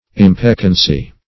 Impeccancy \Im*pec"can*cy\